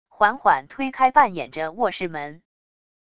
缓缓推开半掩着卧室门wav下载